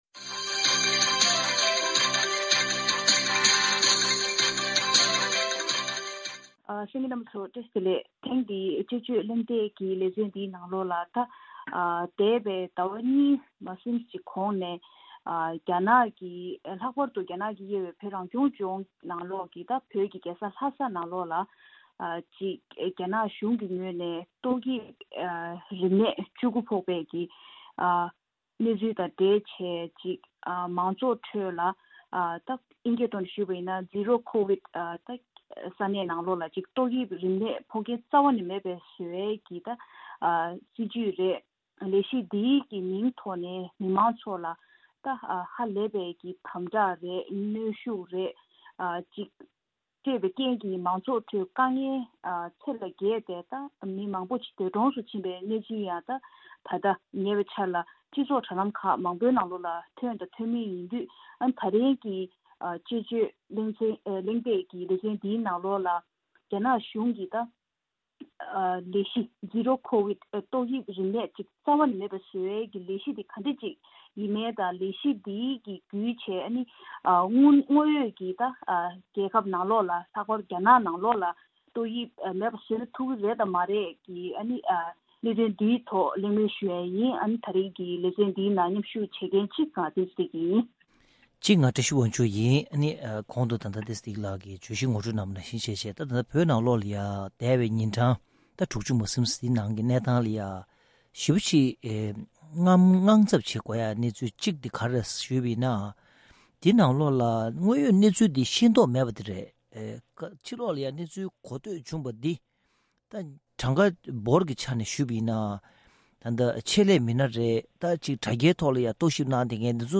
དཔྱད་གླེང་གནང་བའི་ལས་རིམ།